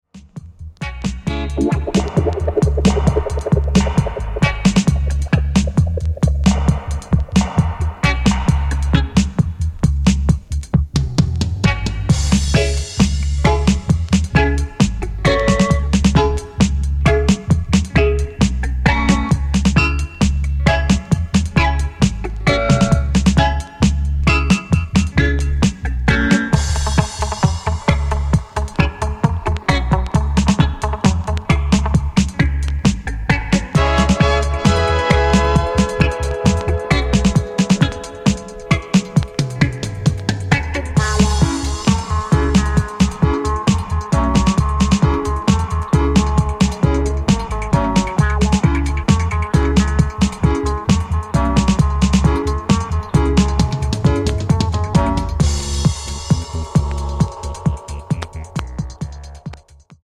Dub. Roots. Reggae.